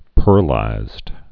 (pûrlīzd)